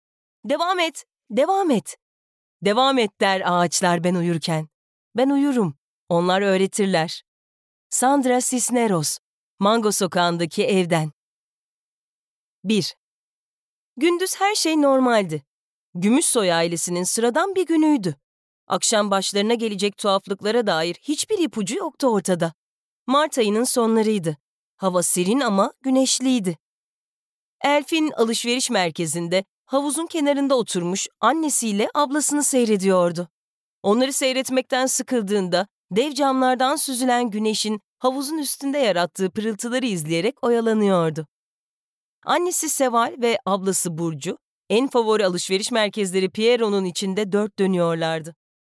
Sesli Kitap
Deneyimli seslendirme sanatçılarının okuduğu, editörlüğümüz tarafından özenle denetlenen sesli kitap koleksiyonumuzun ilk örneklerini paylaşmaktan sevinç duyuyoruz.